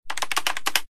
keyboard4.ogg